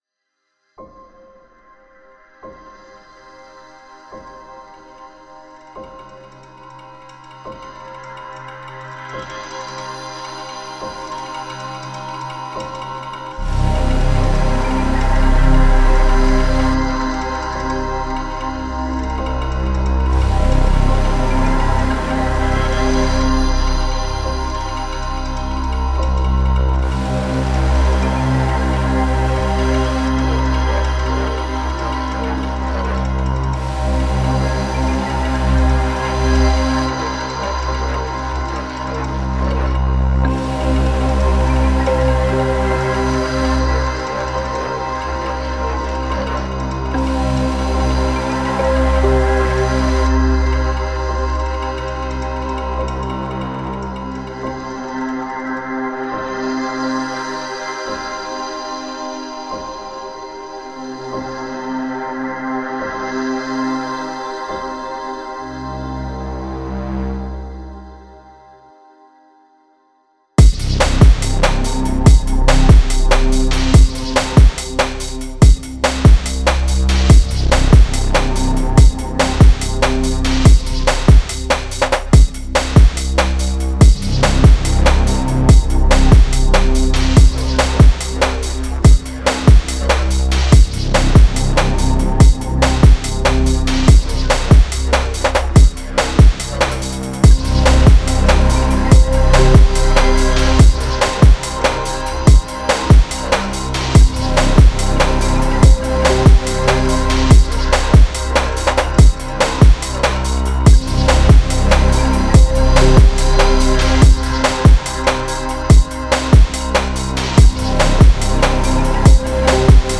Ghostly intro evolves into a determined breakbeat banger.